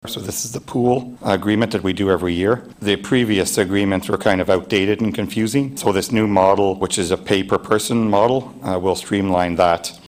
That’s Renfrew Councilor and Chair for the Recreation, Fire, and Protective Services Committee Jason Legris, who shared the proposed agreement at their meeting on Tuesday, February 28th.